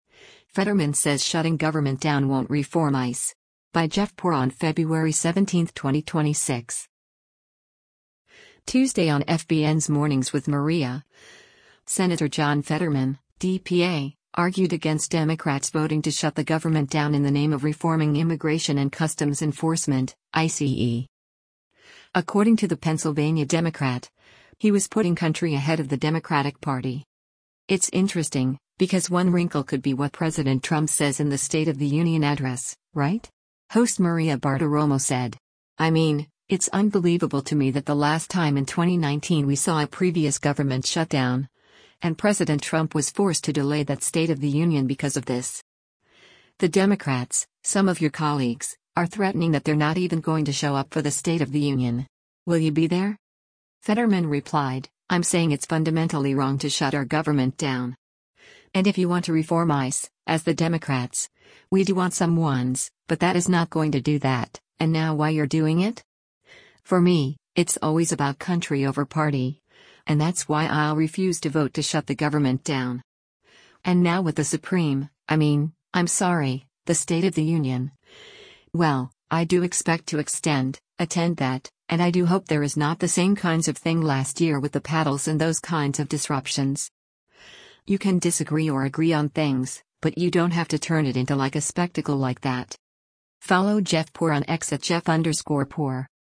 Tuesday on FBN’s “Mornings with Maria,” Sen. John Fetterman (D-PA) argued against Democrats voting to shut the government down in the name of reforming Immigration and Customs Enforcement (ICE).